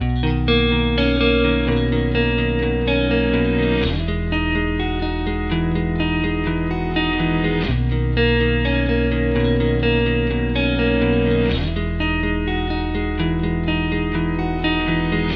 环境吉他弹奏的进展
Tag: 125 bpm Ambient Loops Guitar Electric Loops 2.58 MB wav Key : B